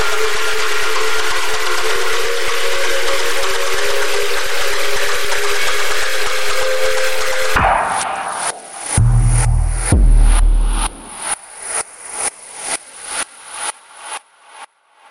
噪声
描述：我们可以用一点Fx来做hmm horror song。
Tag: 140 bpm Weird Loops Fx Loops 591.57 KB wav Key : Unknown